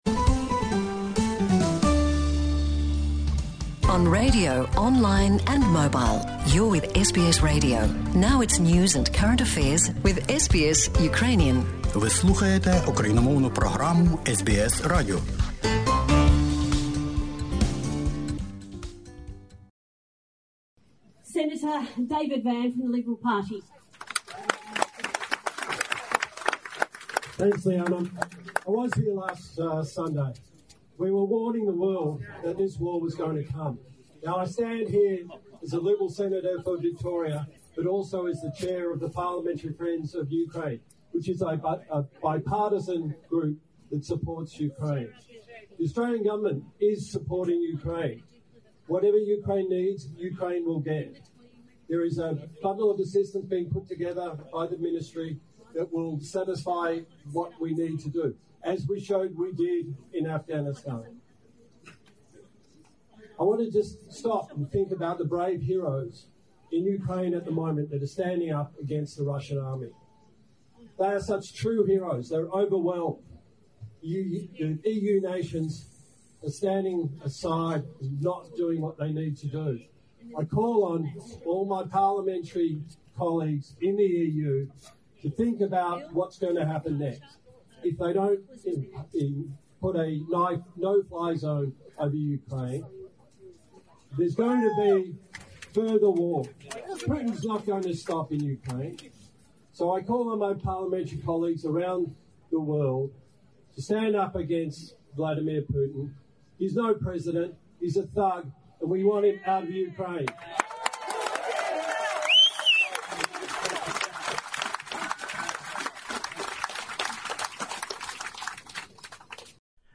Сенатор від Ліберальної партії Австралії у Вікторії Дейвід Вен про війну в Україні у час маніфестації у Мельбурні проти вторгнення російської армії на українські землі...